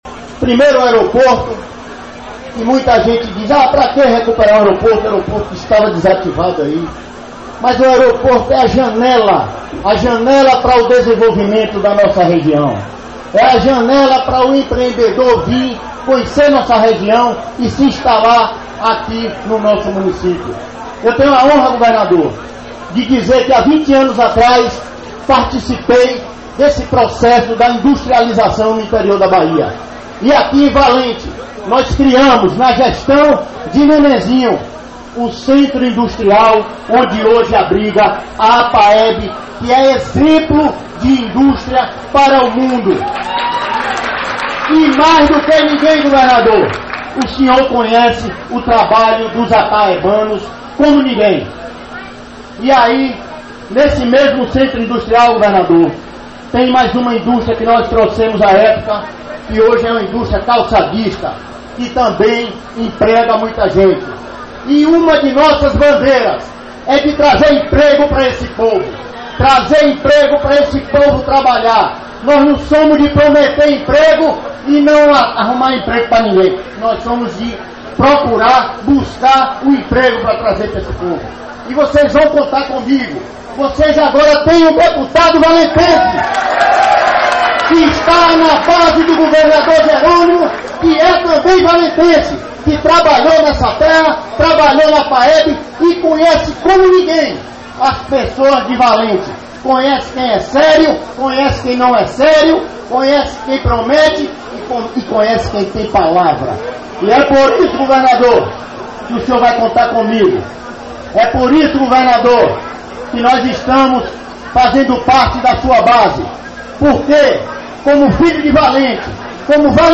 Ao chegar ao lado do centro de abastecimento onde foi montado o palanque para os discursos, Luciano estava lá compondo a primeira fileira e quando o locutor anunciou seu nome ele fez a revelação que muita gente esperava, sua adesão a base governista na Assembleia Legislativa de Bahia.